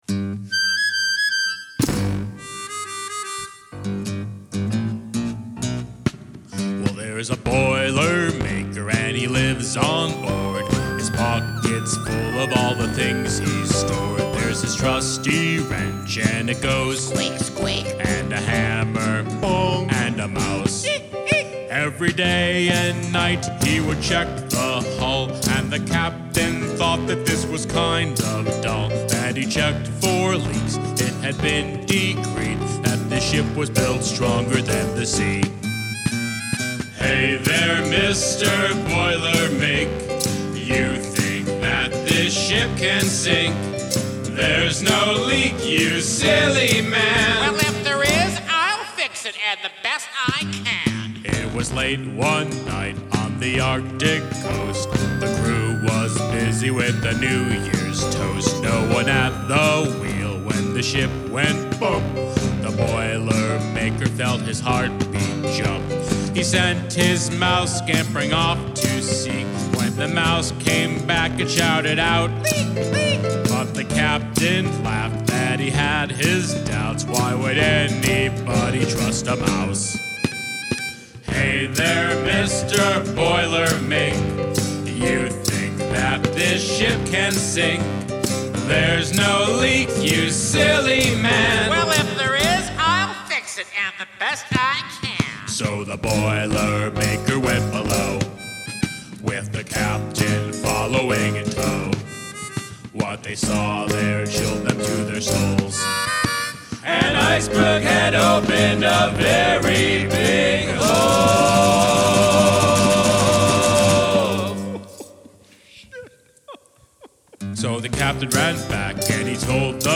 Integrate animal sounds into your song's structure